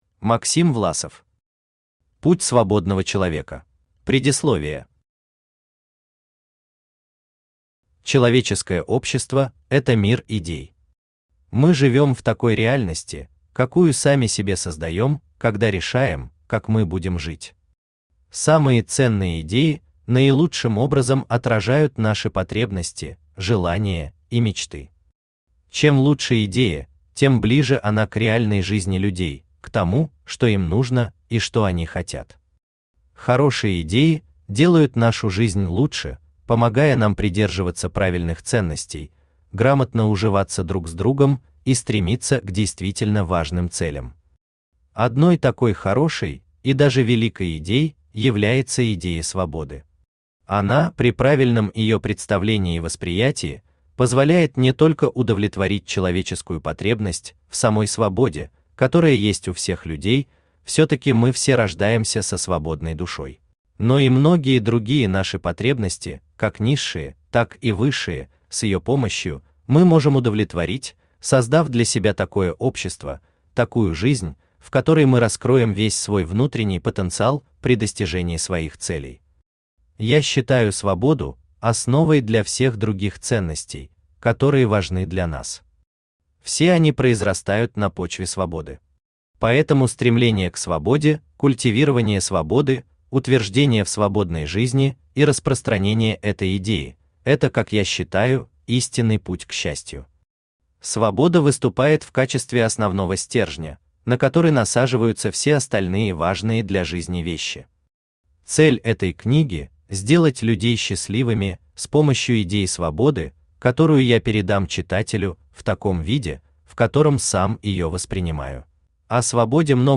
Аудиокнига Путь свободного человека | Библиотека аудиокниг
Aудиокнига Путь свободного человека Автор Максим Власов Читает аудиокнигу Авточтец ЛитРес.